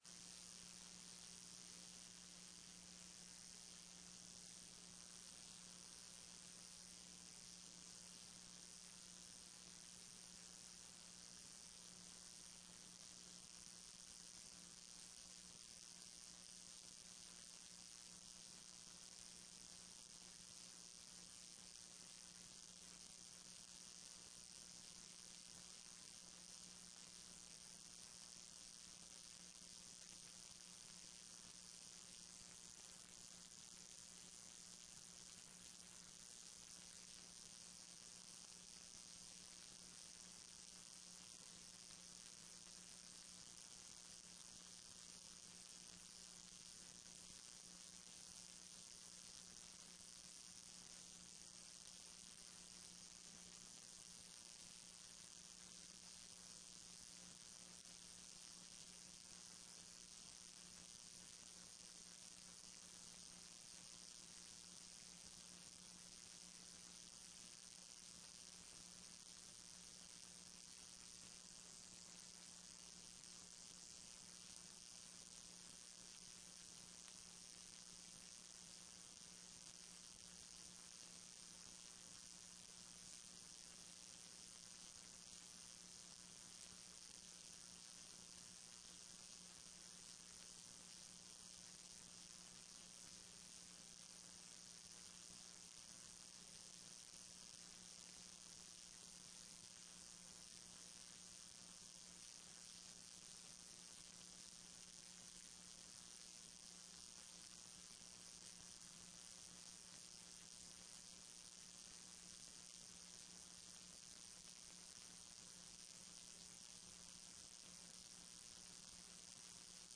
TRE-ES sessão do dia 01-09-14